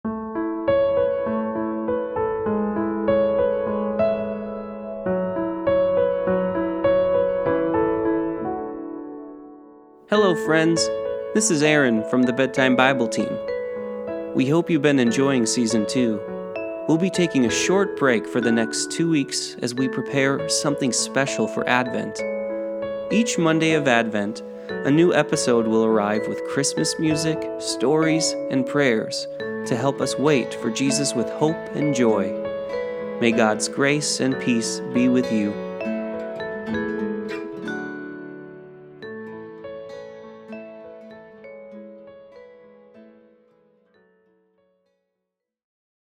Help your children prepare for bed with our prayer and relaxation podcast. Each podcast will feature calming music, scripture and prayers to help children unwind from their day.